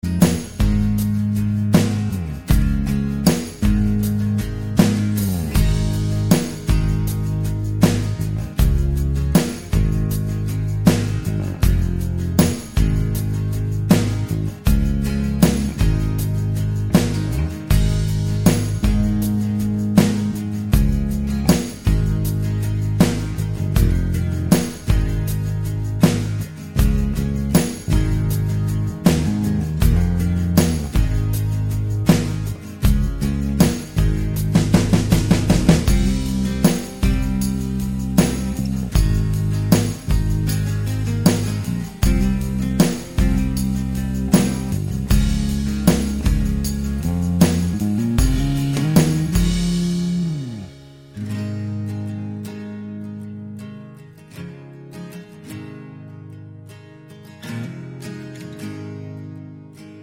no Backing Vocals Country (Male) 2:59 Buy £1.50